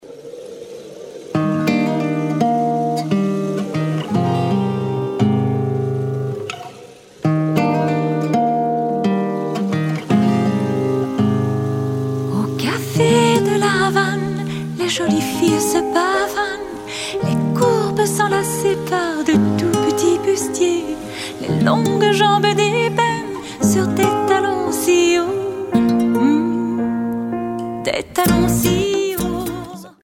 une pièce d’inspiration haïtienne